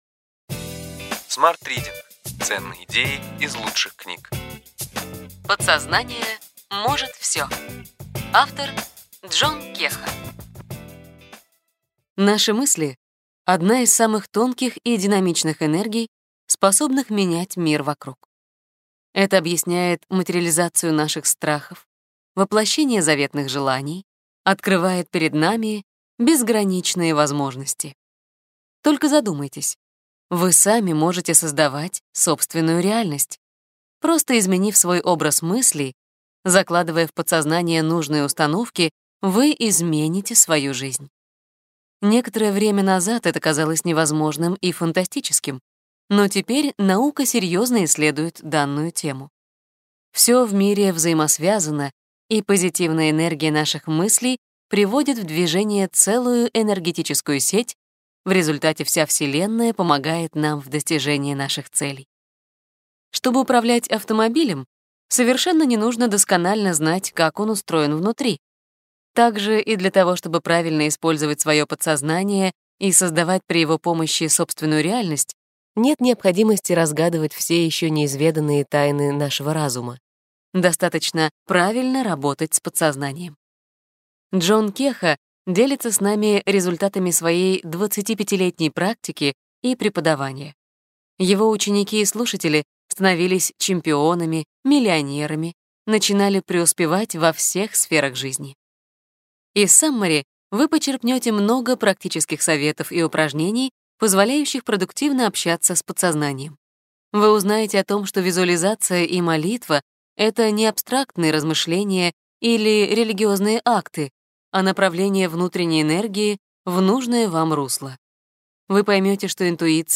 Аудиокнига Ключевые идеи книги: Подсознание может все!